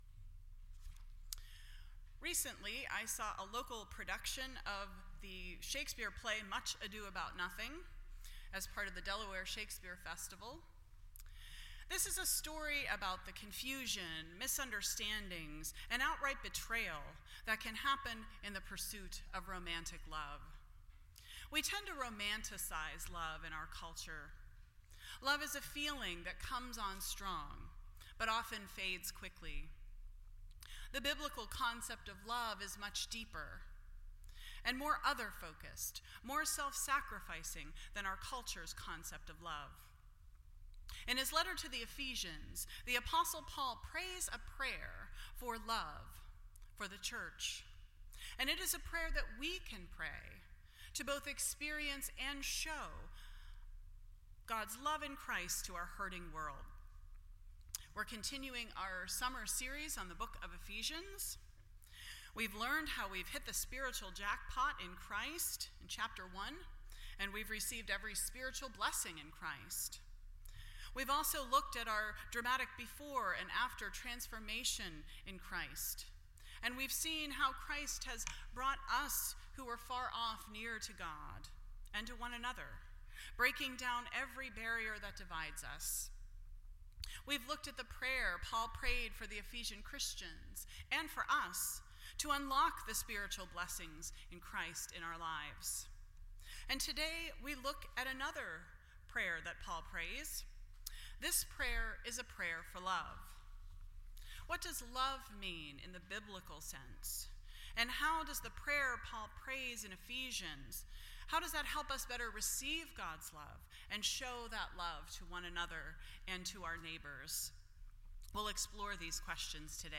Ephesians Service Type: Sunday Morning %todo_render% Share This Story